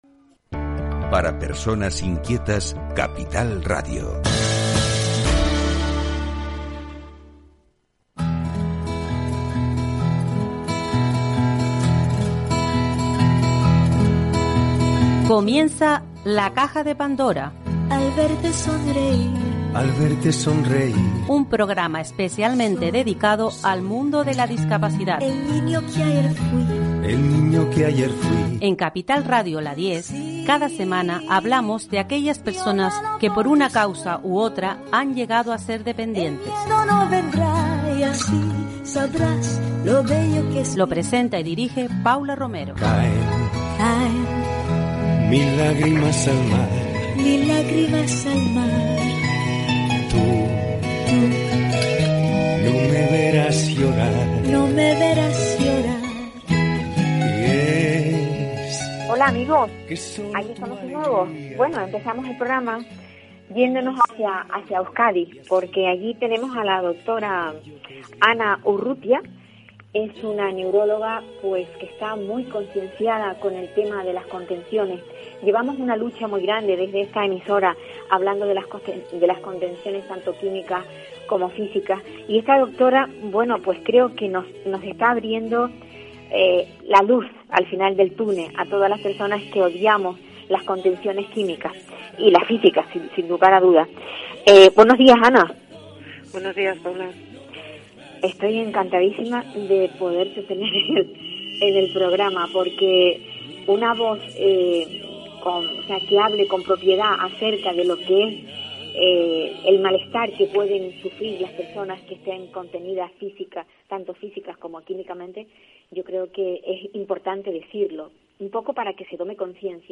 ENTREVISTADA EN LA DIEZ CAPITAL RADIO